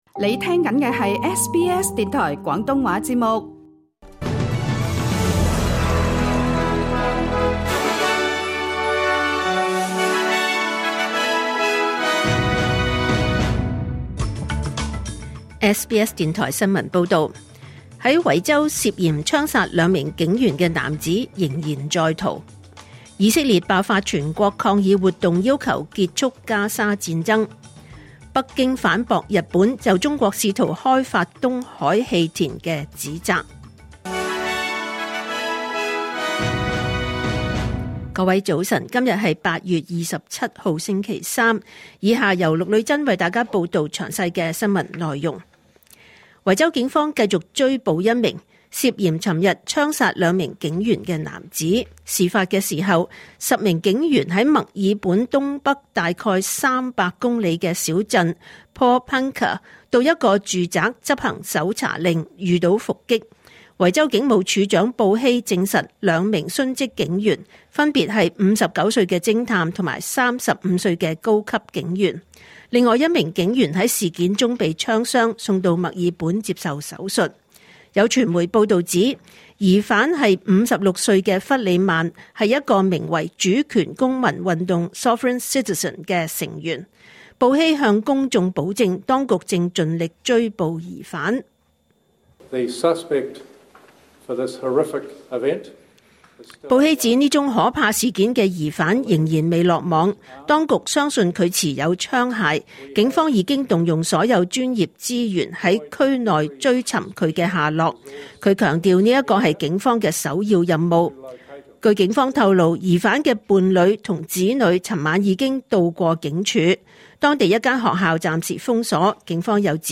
2025年8月27日 SBS 廣東話節目九點半新聞報道。